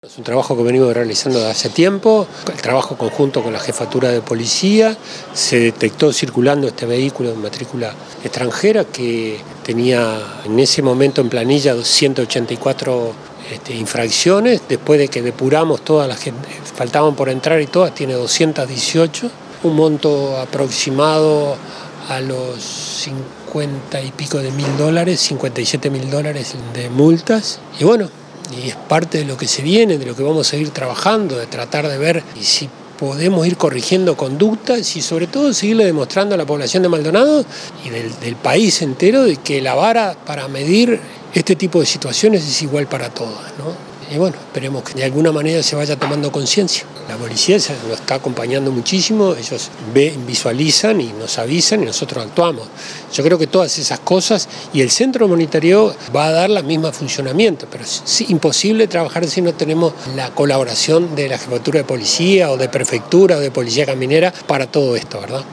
Escuchar la entrevista al director de Tránsito de la Intendencia de Maldonado, Juan Pígola: